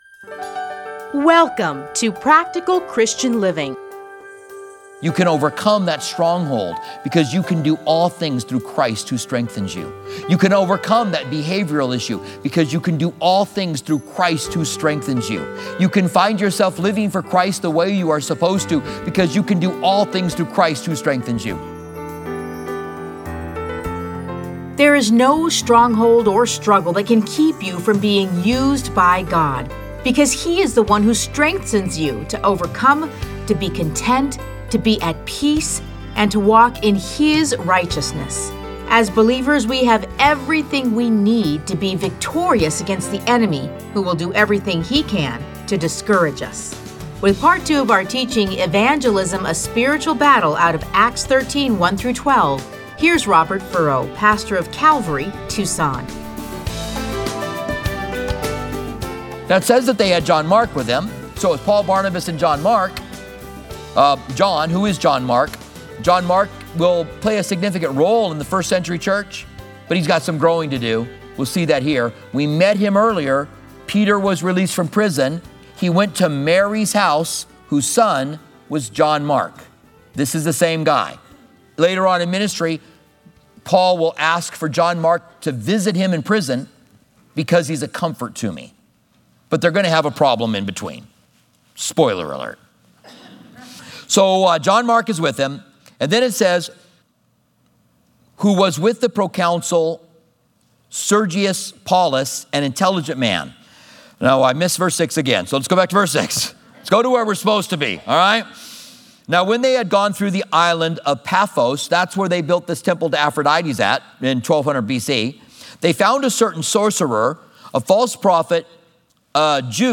Listen to a teaching from Acts 13:1-12.